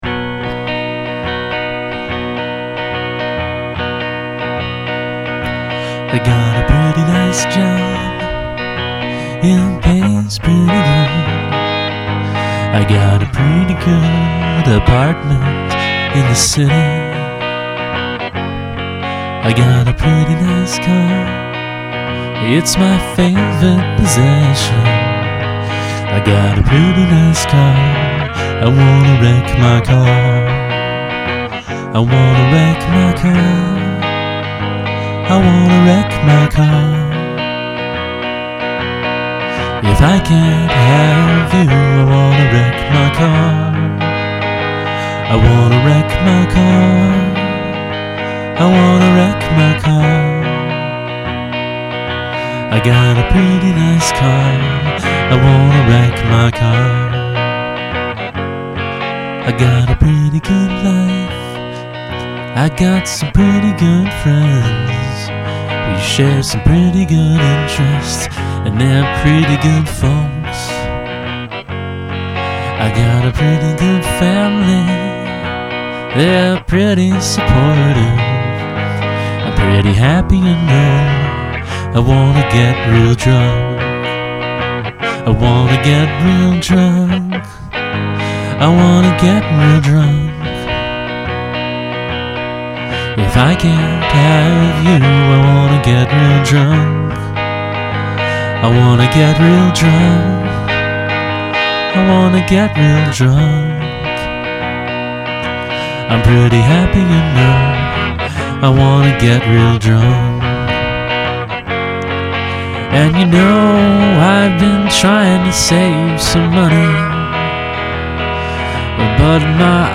And your guitar has such a cool sound! Plingy, I guess.
There's a bit in there where I'm totally expecting a chord change -- but then you don't change the chord.
The lyrics are borderline punk.